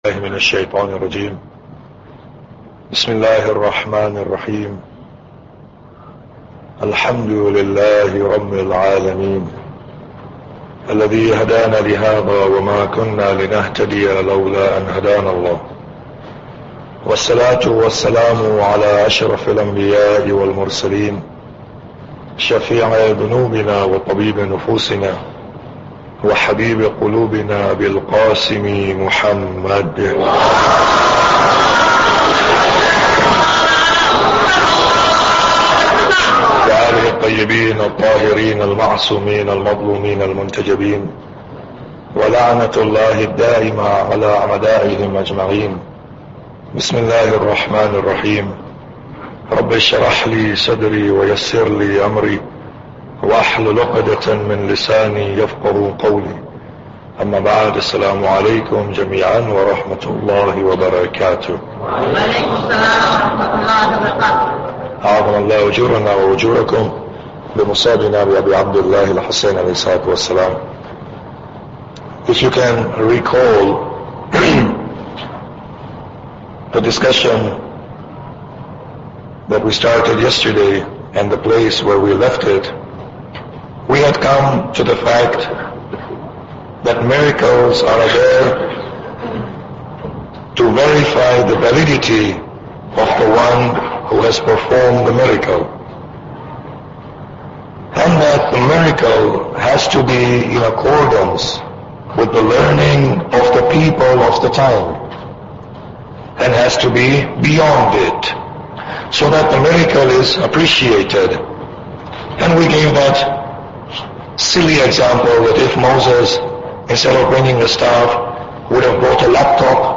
Muharram Lecture 3